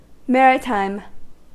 Ääntäminen
Ääntäminen US : IPA : [ˈmær.ə.ˌtɑɪm] UK : IPA : /ˈmæ.ɹɪˌtaɪm/ Tuntematon aksentti: IPA : /ˈmæritaim/ Lyhenteet ja supistumat (laki) Mar.